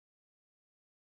atarihit.wav